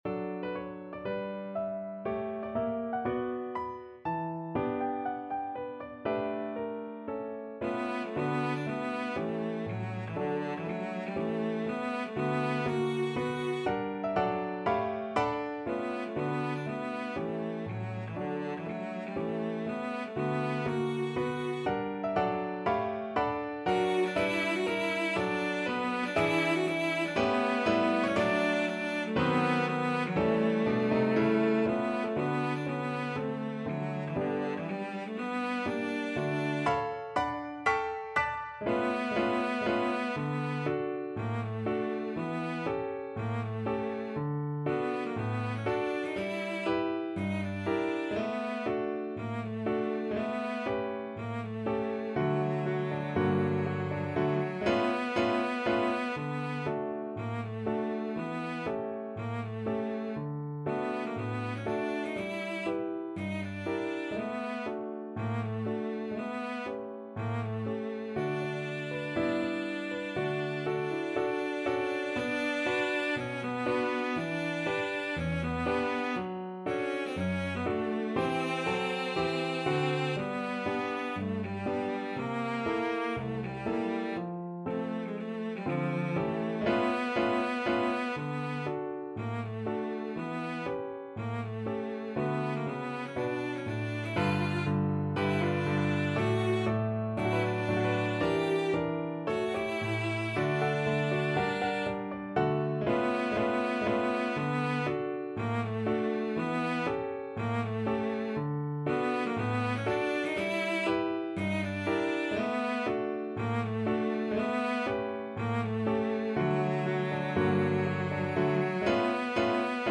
~ = 120 Moderato
4/4 (View more 4/4 Music)